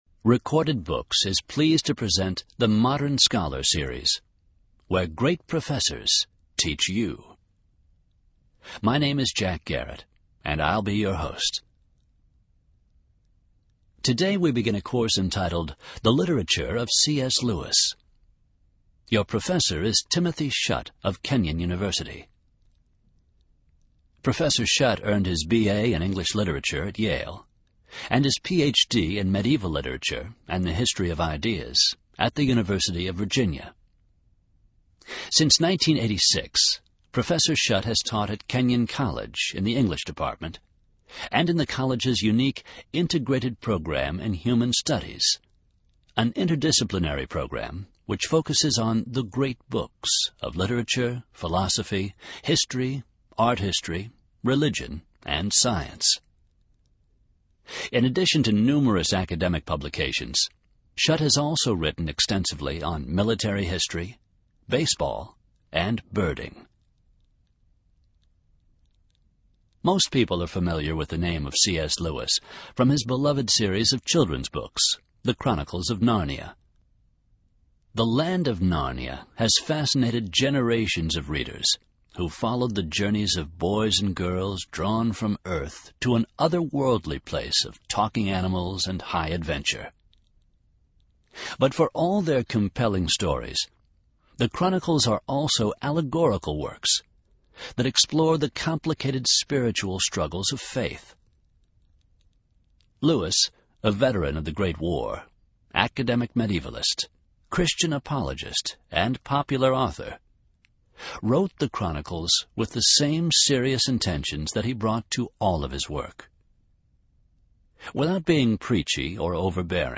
In this lecture professor